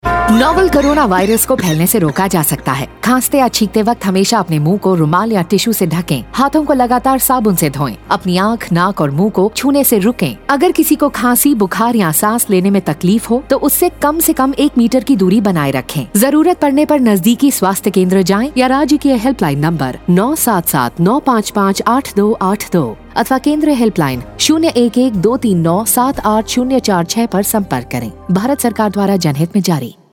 Radio spot on key behaviours COVID-19_Hindi_Chandigarh
Radio PSA